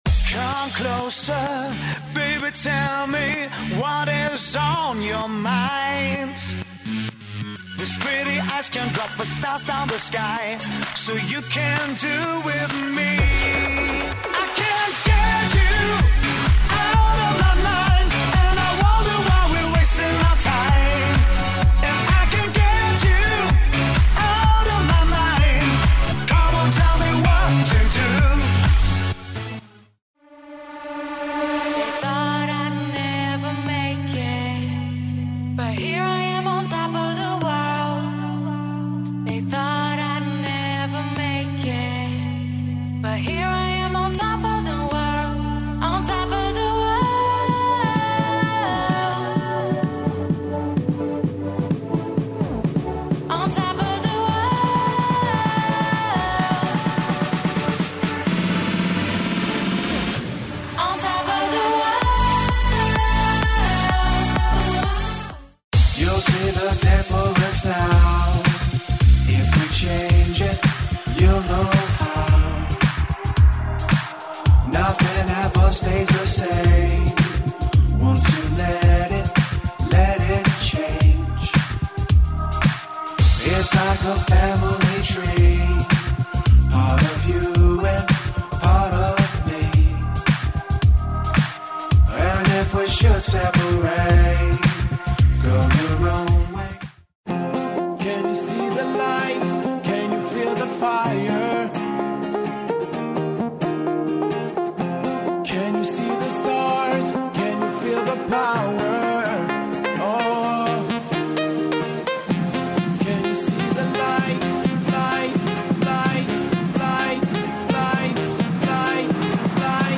styl - pop/dance/chillout/latino